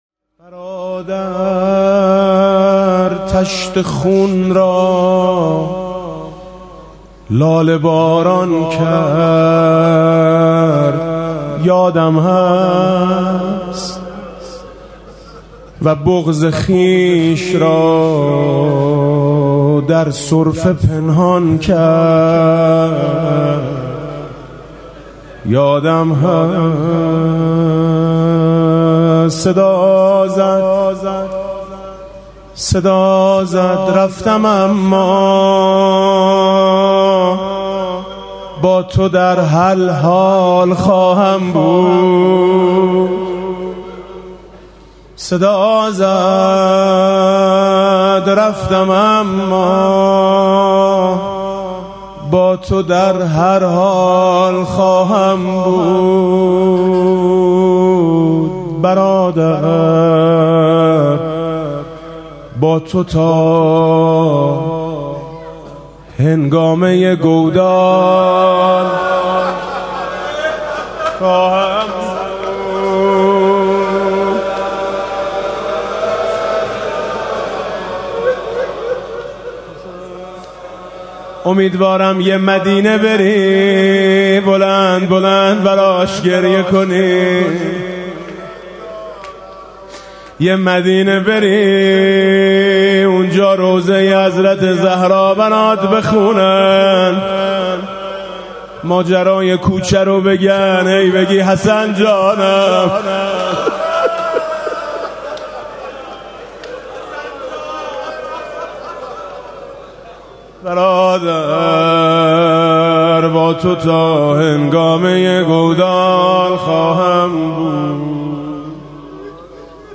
گلوی سرخ عبدالله آهنگ حسن دارد (روضه